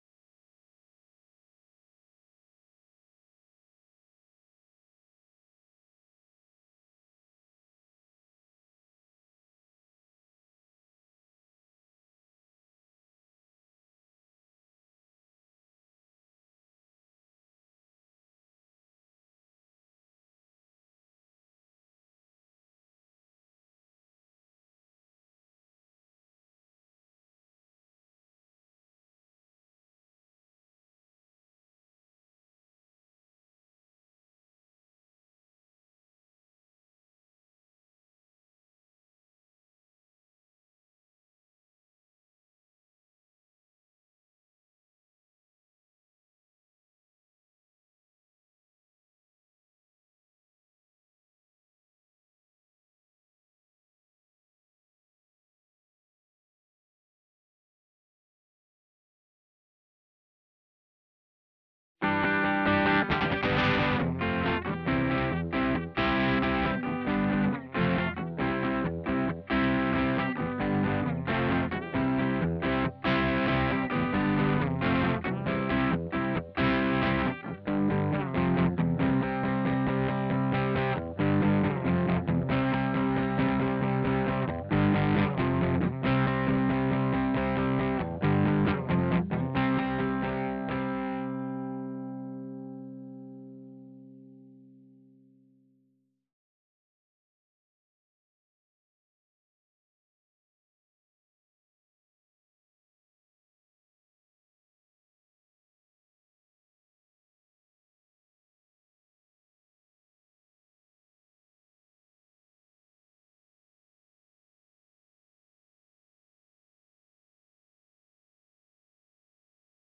Faith_Crunch Guitar 1 render 001.wav